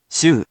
In romaji, 「しゅ」 is transliterated as 「shu」which sounds sort of like the saying the English word「shoe」.